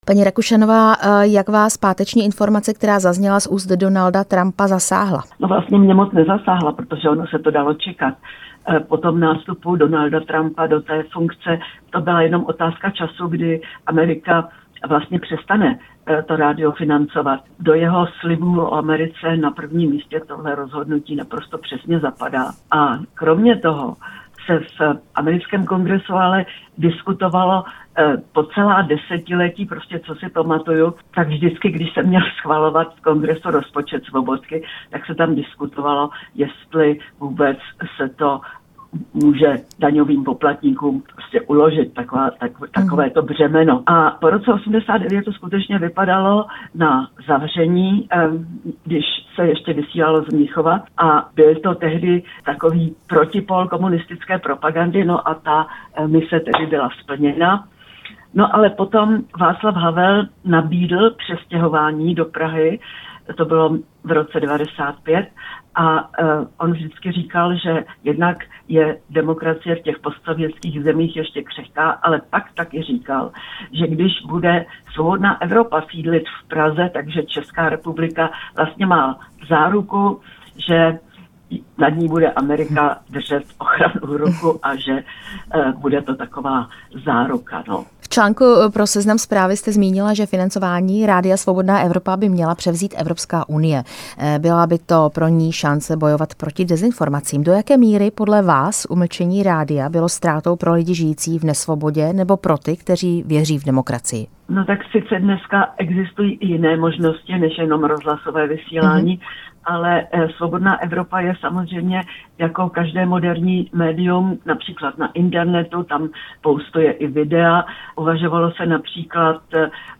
Hostem Rádia Prostor byla novinářka Lída Rakušanová, která léta ze Svobodné Evropy přinášela zpravodajství do komunistického Československa.
Rozhovor s novinářkou Lídou Rakušanovou